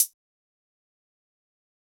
Closed Hats
Hat 16.wav